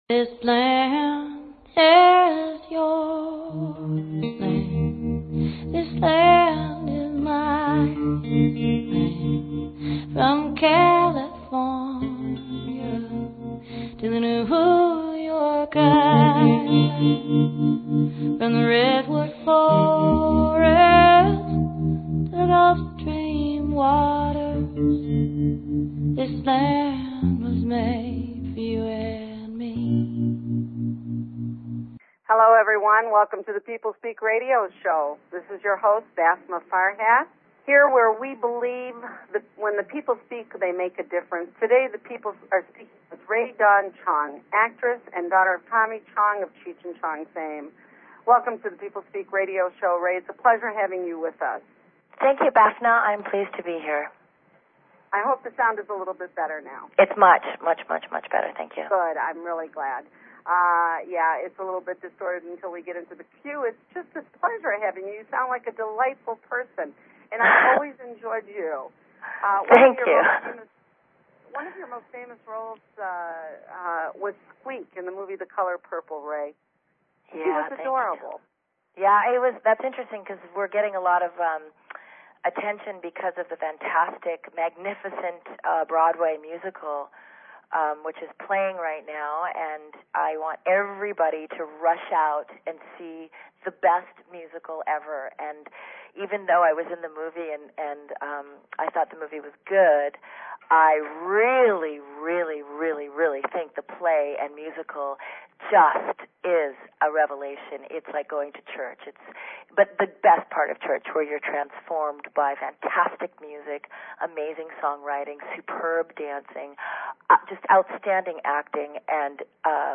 Talk Show Episode, Audio Podcast, The_People_Speak and Rae Dawn Chong on , show guests , about , categorized as Entertainment,TV & Film
Guest, Rae Dawn Chong